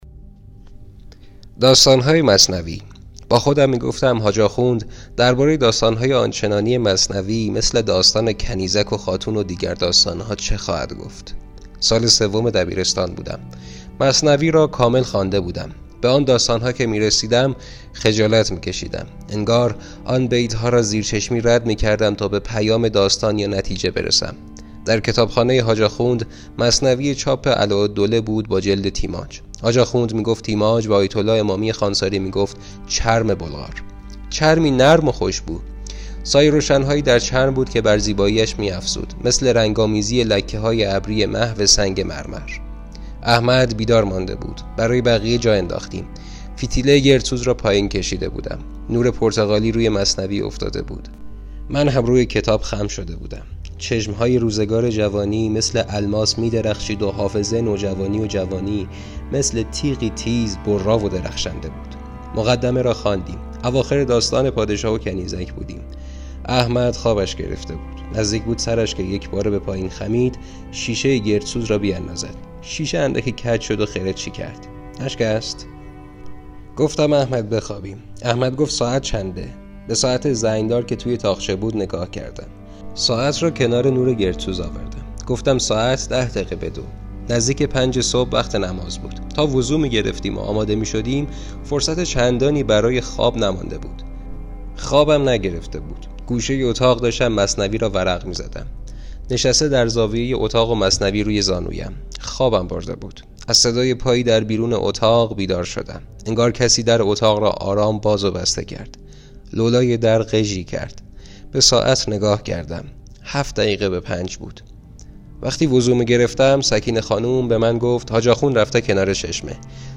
روایتی از کتاب «حاج آخوند» /۳